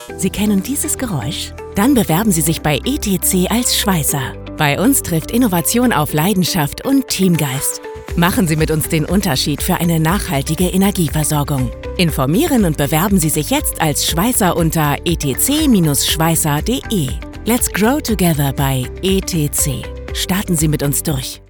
Werbung - Tourismus Canada intensiv, ruhig